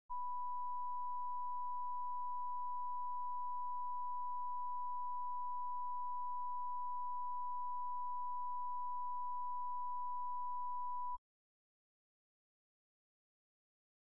Conversation: 752-010
Recording Device: Oval Office
Location: Oval Office